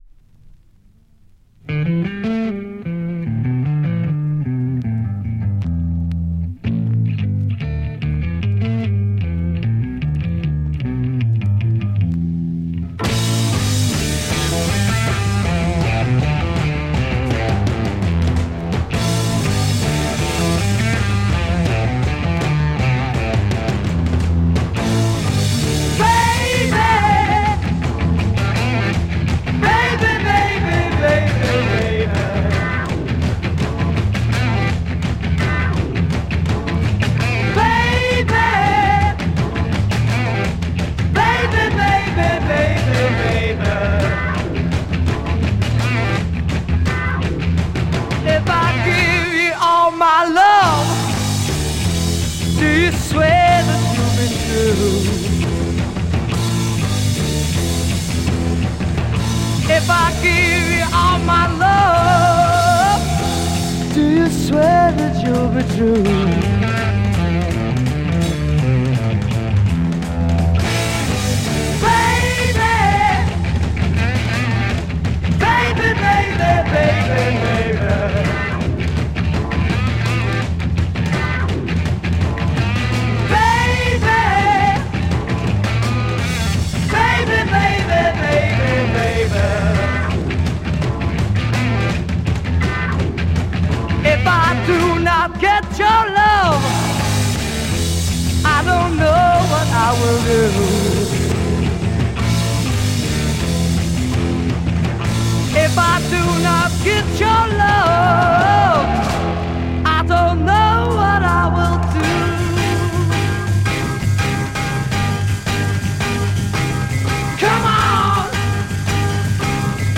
Killer Dutch deep psych rock groover